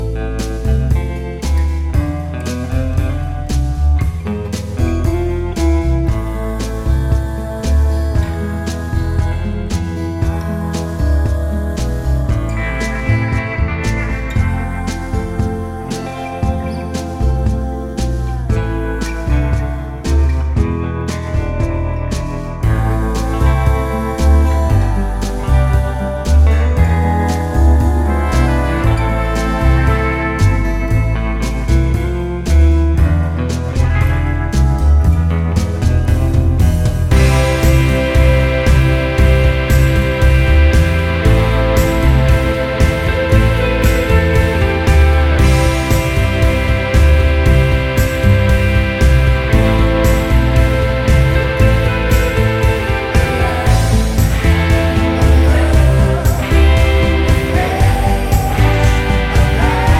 no Backing Vocals Jazz / Swing 3:26 Buy £1.50